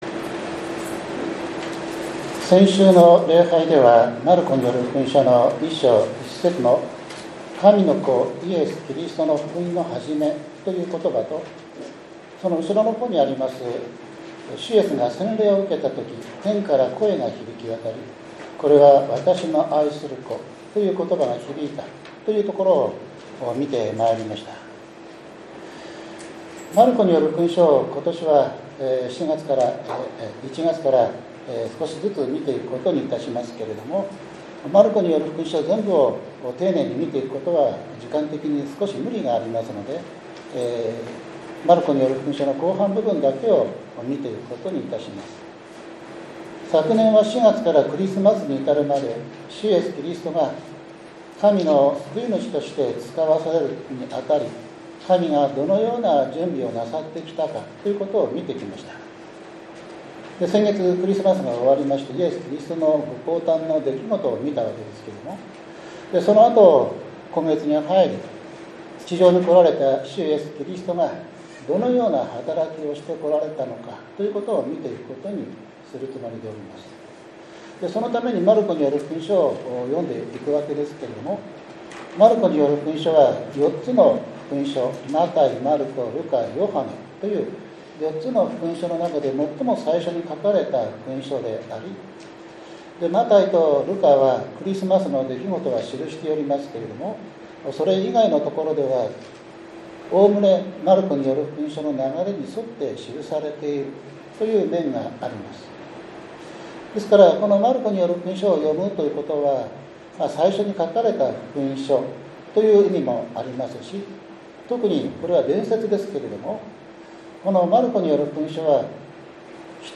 １月１２日（日）主日礼拝